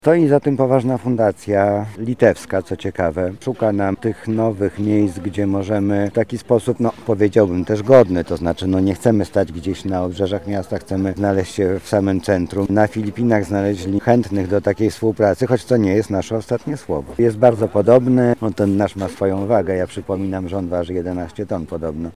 Na temat zaangażowania we współpracę i chęci dalszego rozwoju mówi Mariusz Banach, Zastępca prezydenta ds. Oświaty i Wychowania: